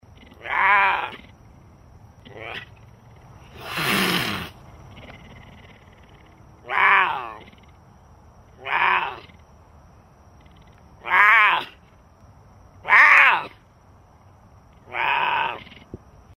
Звуки пумы
Шипение и мяуканье